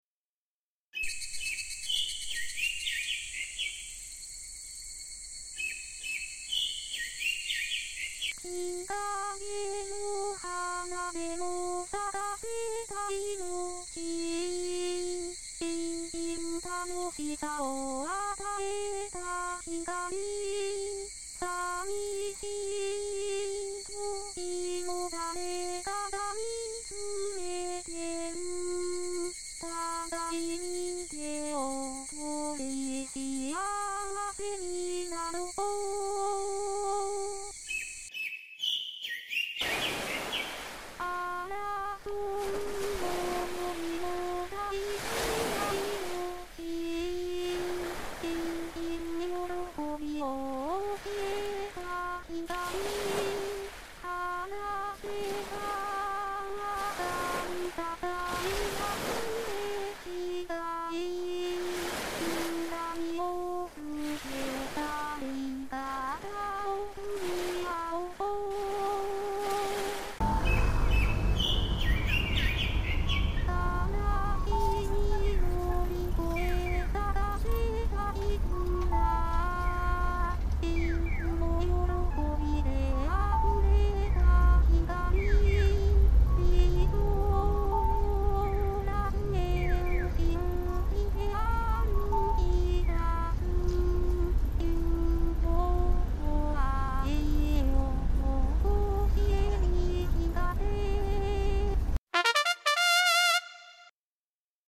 今回は沖縄メロディー風になりました。